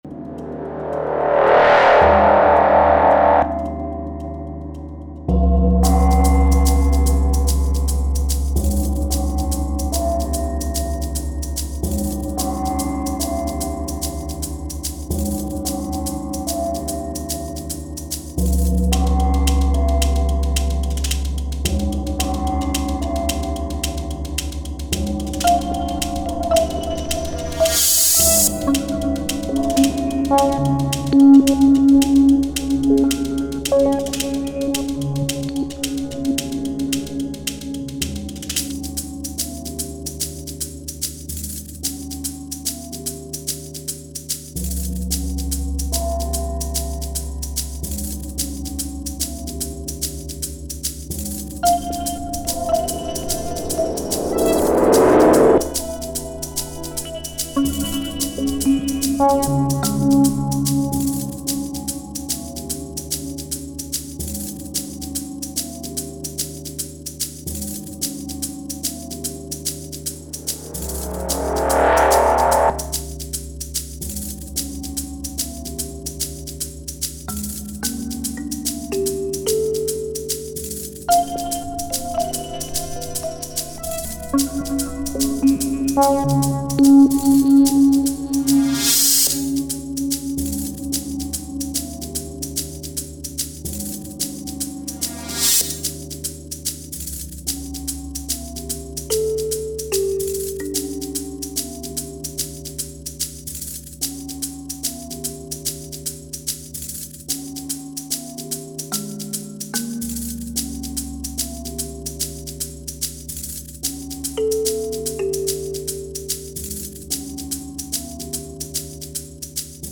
plus synthesizers